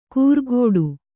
♪ kūrgōḍu